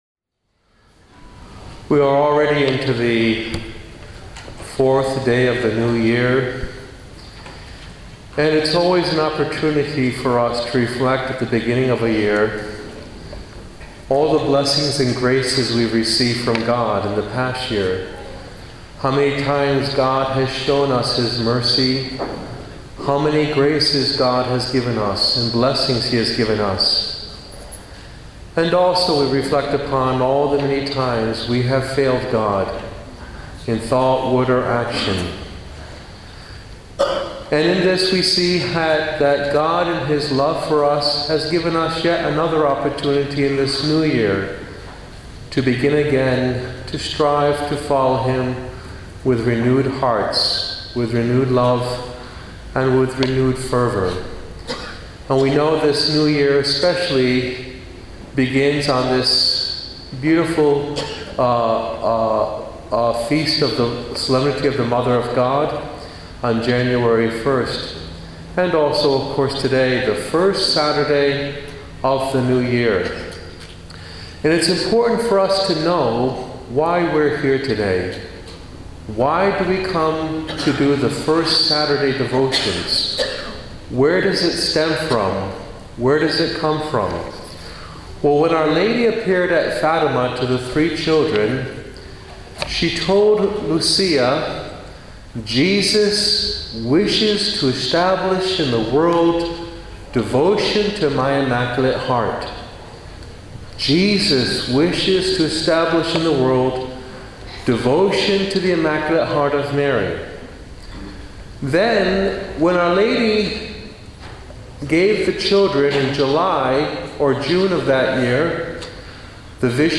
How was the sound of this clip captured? during the "Day With Mary" held at Pater Noster Church in Myaree, Western Australia